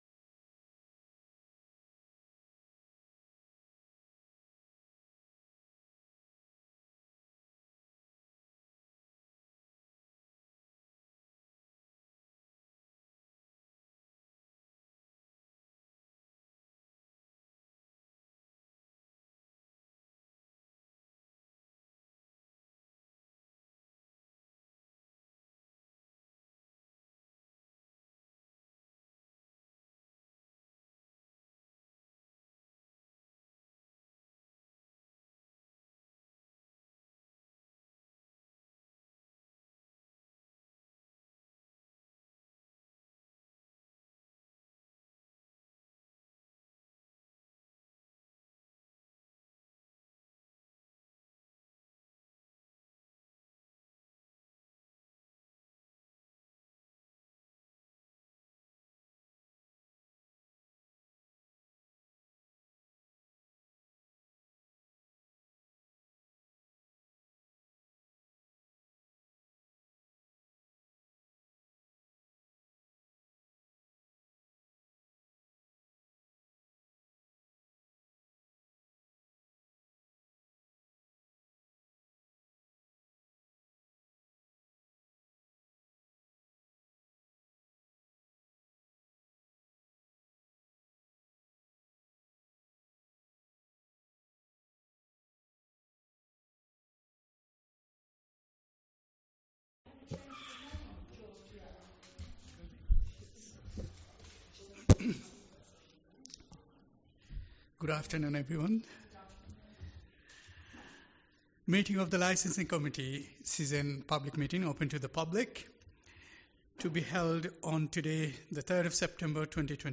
Committee Licensing Committee Meeting Date 03-09-24 Start Time 5.30pm End Time 6.23pm Meeting Venue Coltman VC Room, Town Hall, Burton upon Trent Please be aware that not all Council meetings are live streamed.